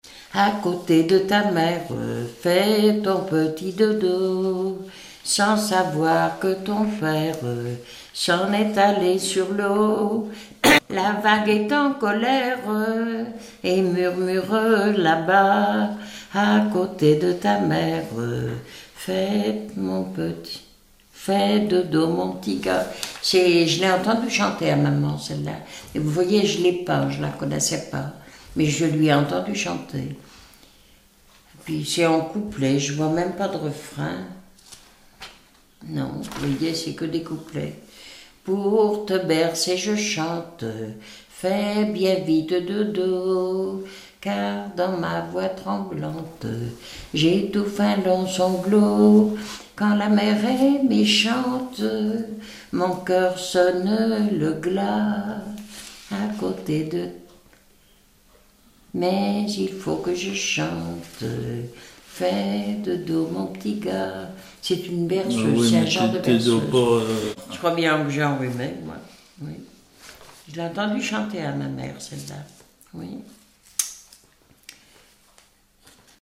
Berceuses diverses
Pièce musicale inédite